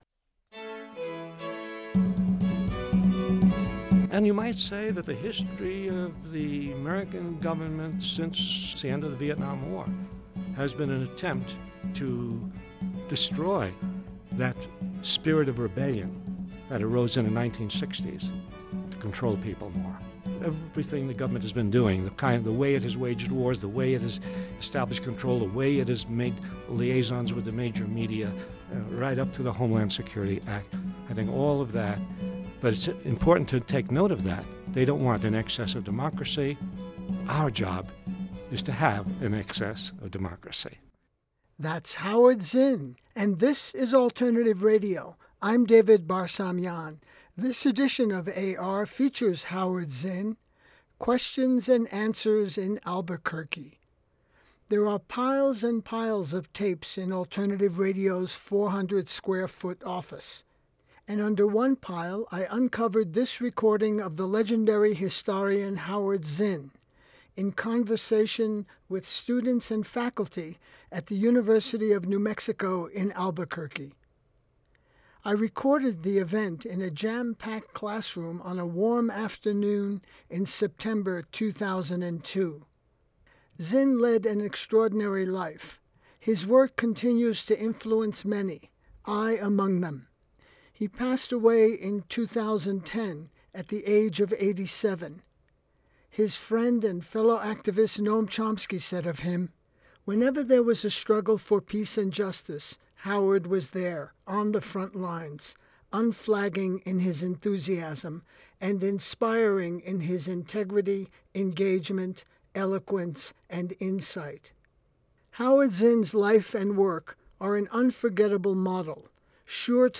Howard Zinn – Q&A in Albuquerque